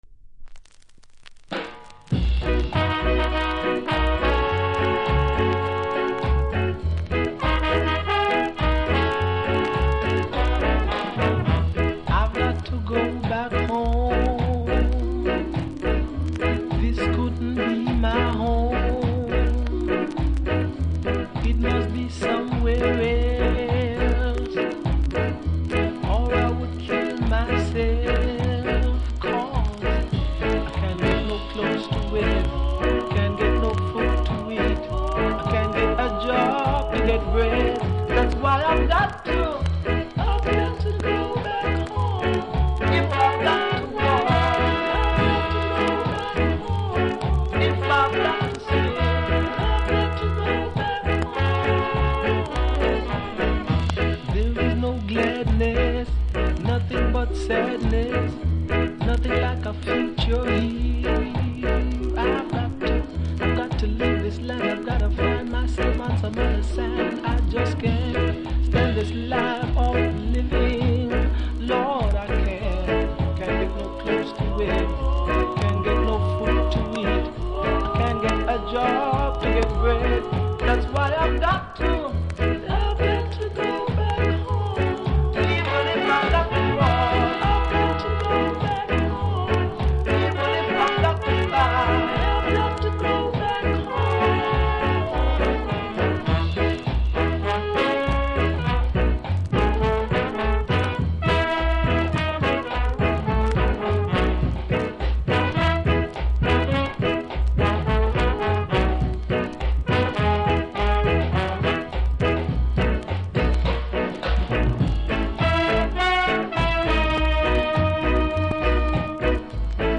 見た目キズは少ないですが所々ノイズ感じますので試聴で確認下さい。
ジャマイカ盤なので両面プレス起因のノイズあります。